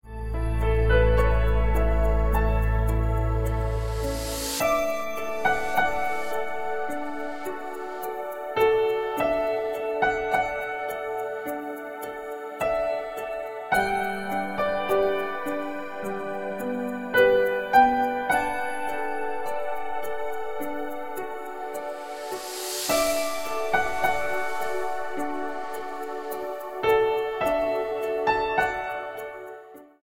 104 BPM
Chirpy hypnotic ostinato with
bass groove and voice accents.